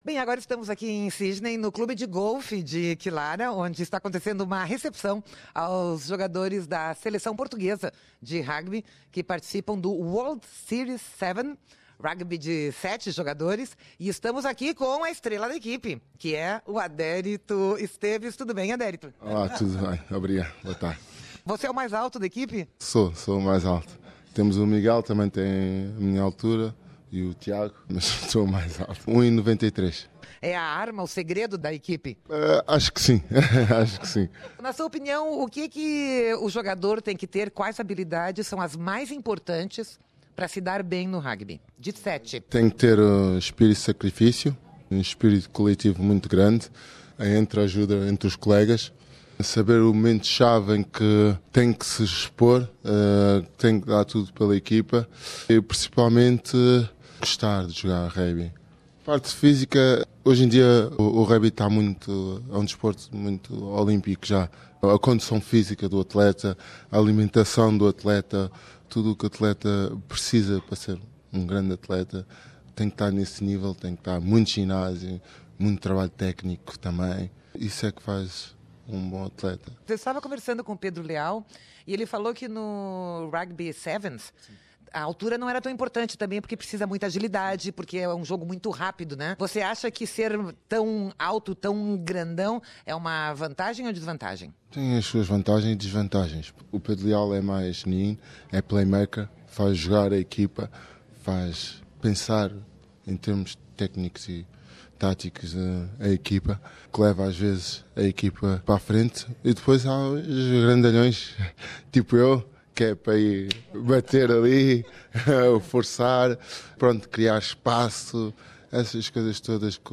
Nesta entrevista ao Programa Português da Rádio SBS em Sydney